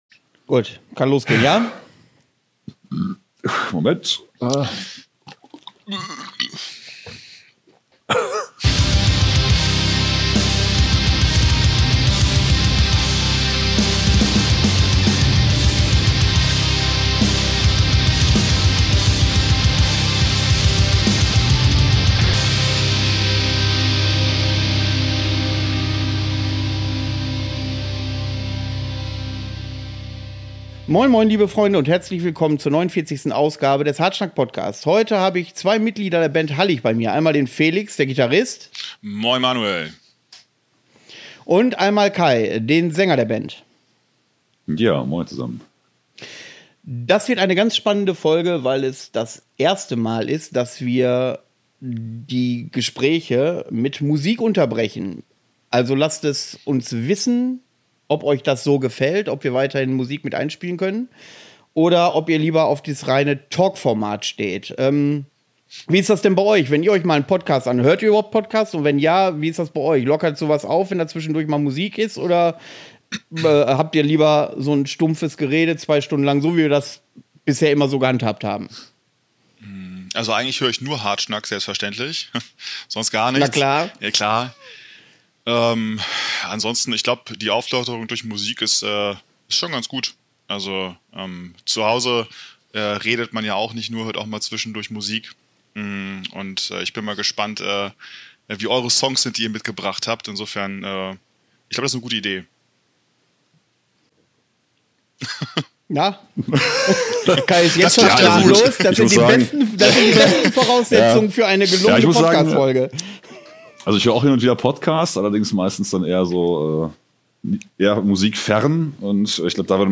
Für ordentlich Diskussionsstoff ist also mal wieder gesorgt. Auch musikalisch gibt es in dieser Folge von Hallig etwas zu hören.